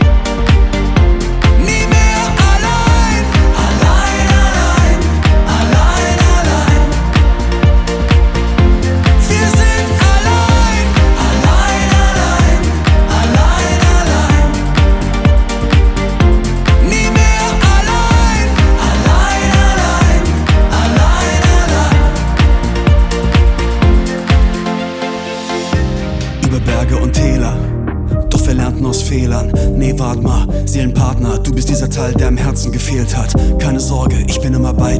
Жанр: Поп
# German Pop